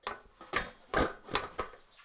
Aurora.3/sound/effects/ladder3.ogg
ladder3.ogg